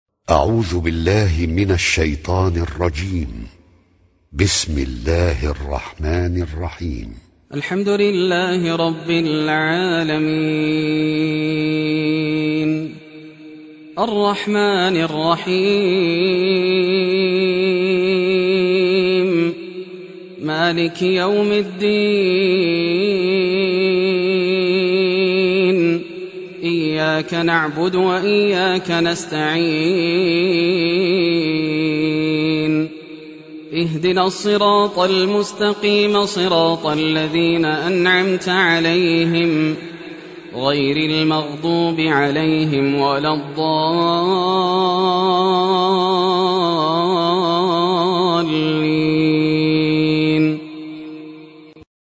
Surah Fatiha Recitation by Yasser Al Dossari
Surah Fatiha, listen or play online mp3 tilawat / recitation in the voice of Yasser Al Dossari.
1-surah-fatiha.mp3